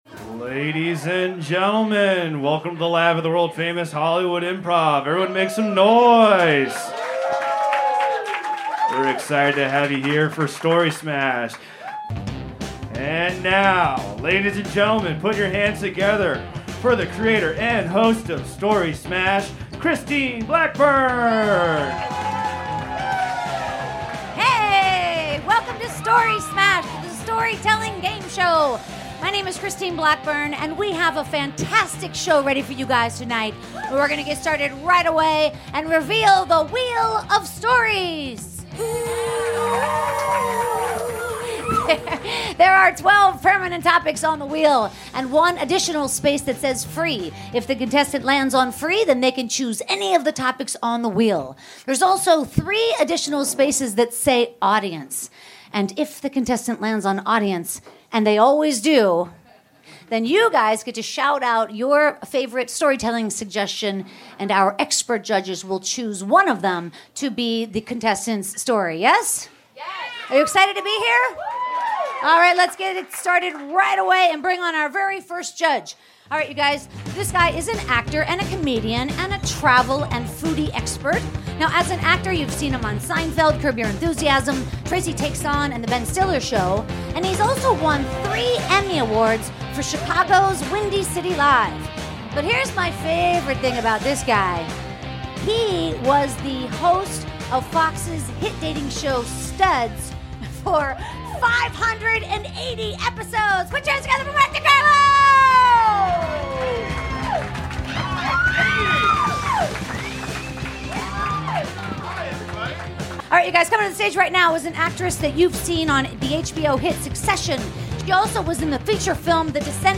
583 - Story Smash the Storytelling Gameshow LIVE at The Hollywood Improv!